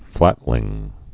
(flătlĭng) also flat·lings (-lĭngs)